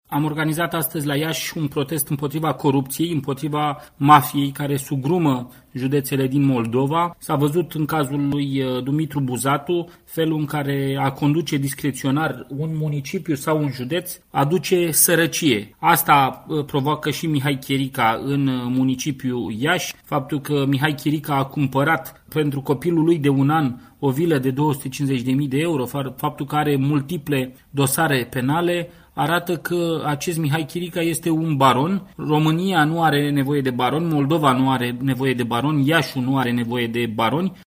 (AUDIO/FOTO) Protest AUR în faţa Primăriei Iaşi cu sute de persoane